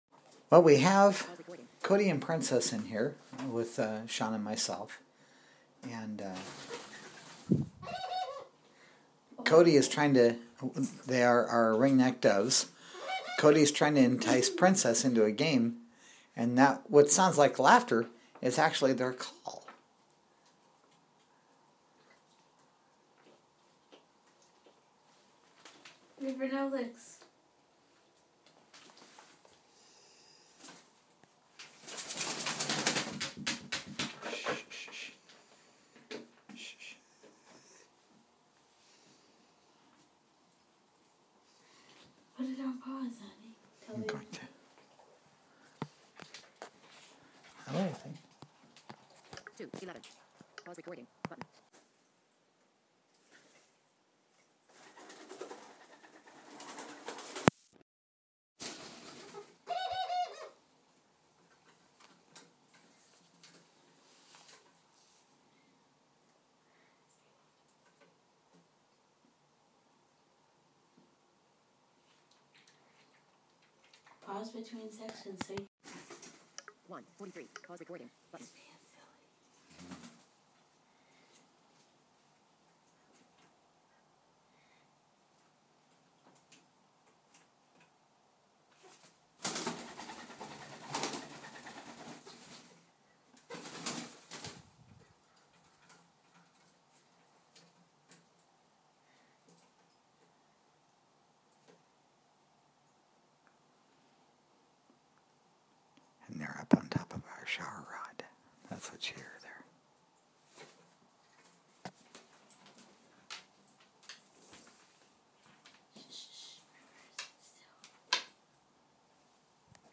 The two doves are playing with each other in our bathroom.